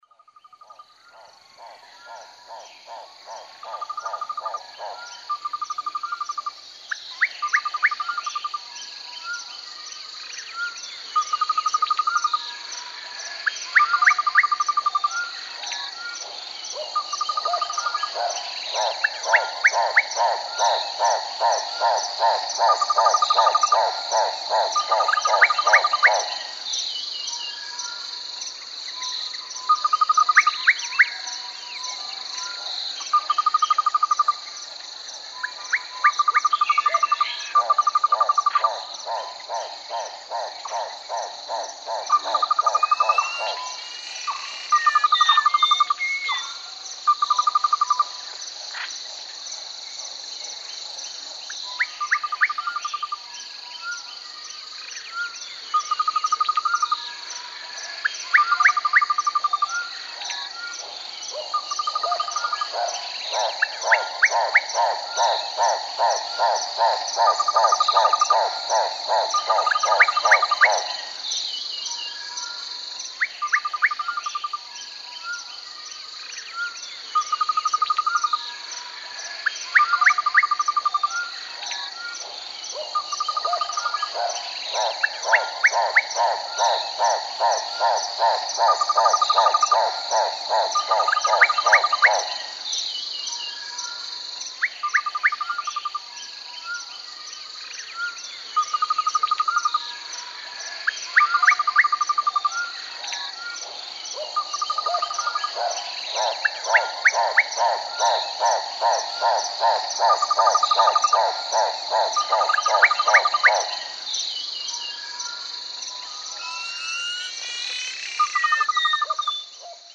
Звуки леса
Африканский лес, шум дня